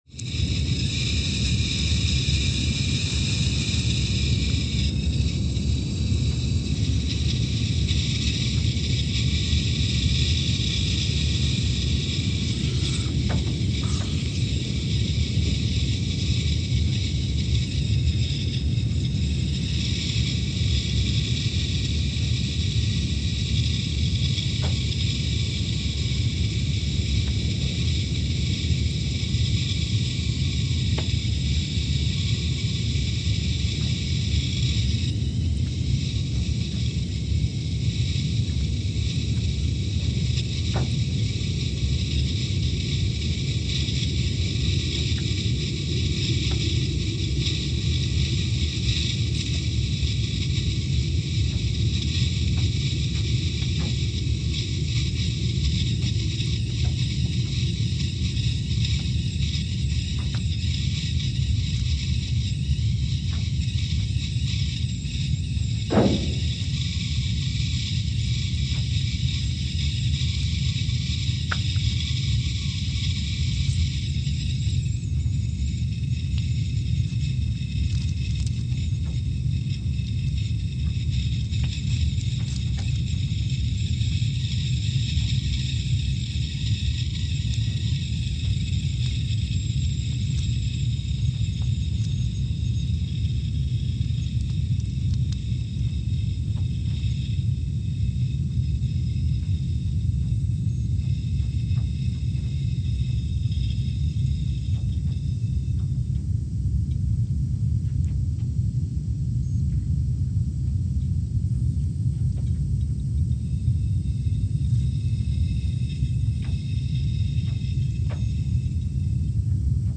Scott Base, Antarctica (seismic) archived on August 20, 2019
Sensor : CMG3-T
Speedup : ×500 (transposed up about 9 octaves)
Loop duration (audio) : 05:45 (stereo)
Gain correction : 25dB